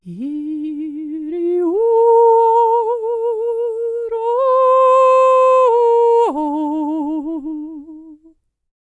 K CELTIC 28.wav